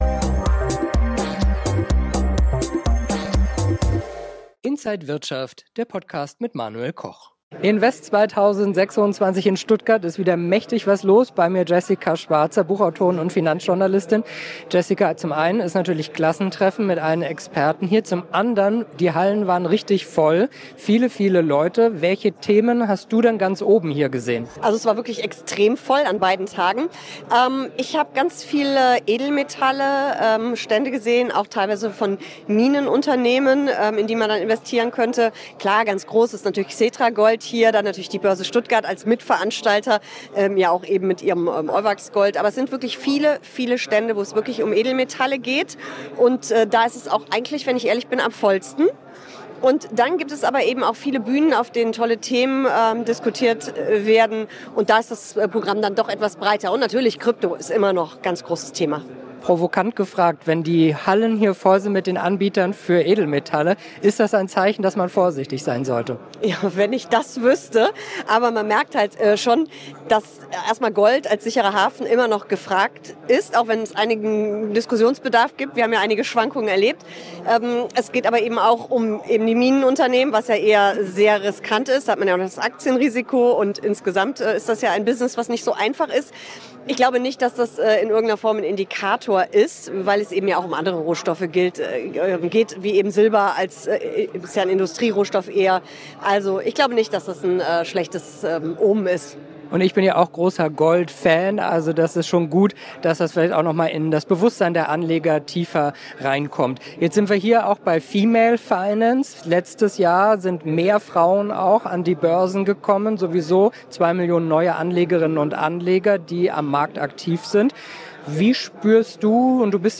Alle Details im Interview von Inside